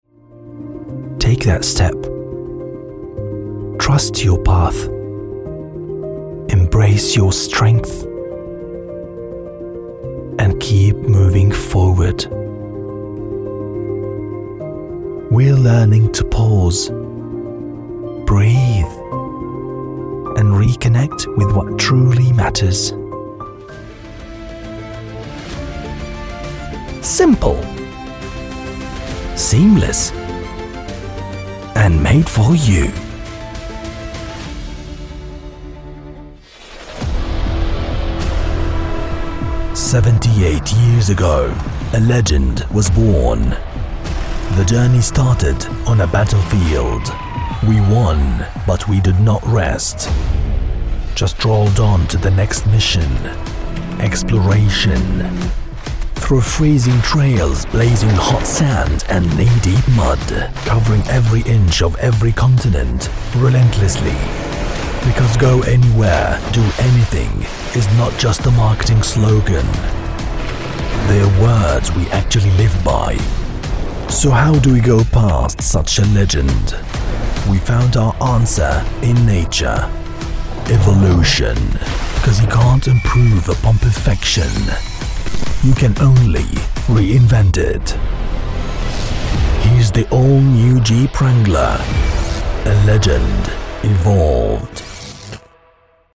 Arabic, Middle Eastern (Egypt, Saudi Arabia, Kuwait), Male, Studio, 30s-50s